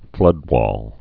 (flŭdwôl)